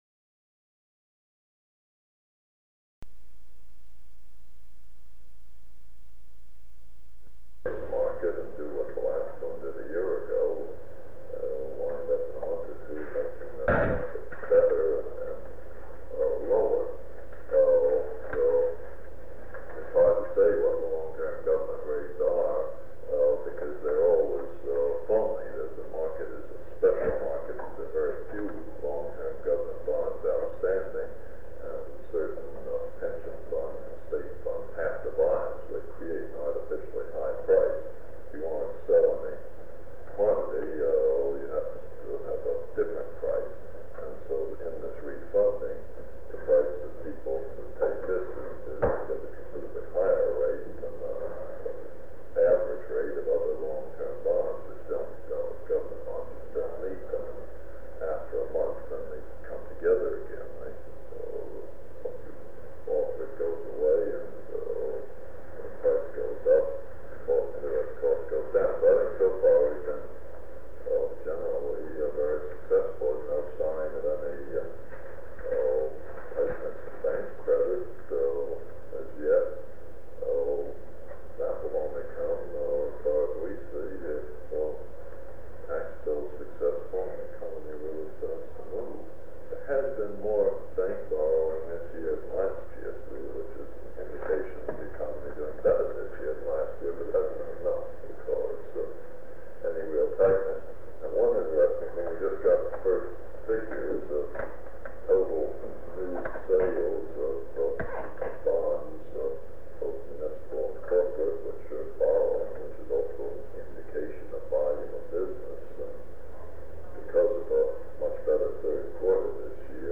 Sound recording of a meeting held on September 12, 1963, between President John F. Kennedy and economic advisers.
Secret White House Tapes | John F. Kennedy Presidency Meetings: Tape 110.